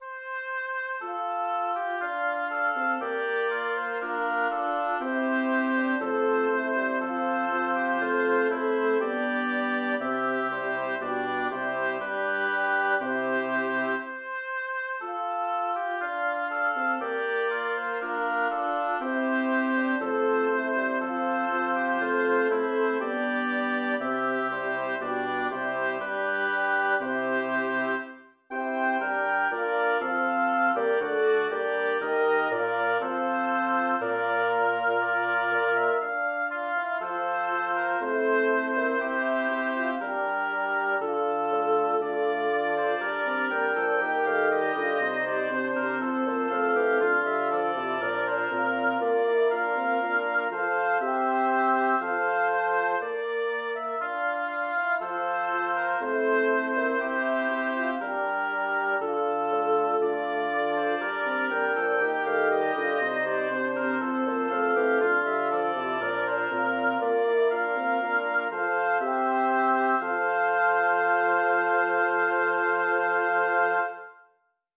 Title: O dio, che potria far Composer: Teodoro Riccio Lyricist: Number of voices: 5vv Voicing: SATTB Genre: Secular, Canzone
Language: Italian Instruments: A cappella